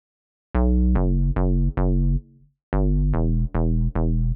35 Bass PT 1-4.wav